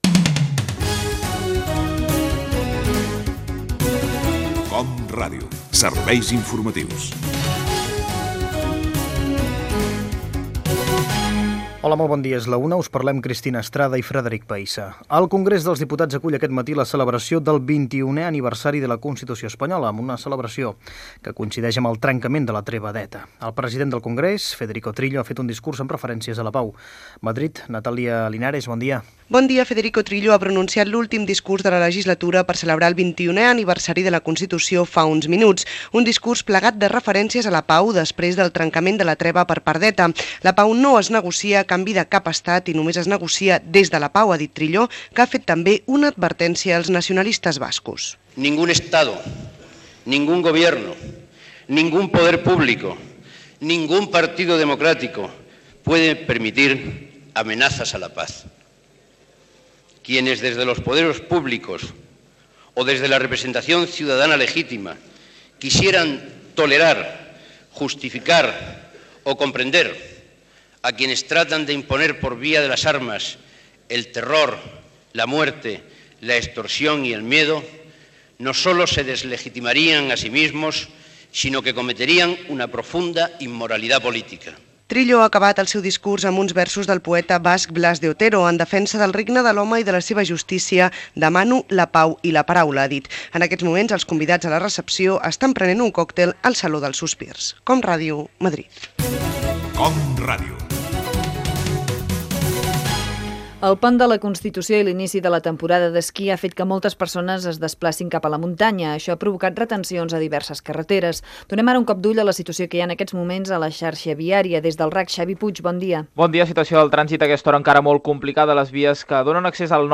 Careta del programa, informació dels 21 anys de la Constitució Espanyola (discurs de Federico Trillo), informació del trànsit en el pont de la Constitució, Txetxènia. Esports
Informatiu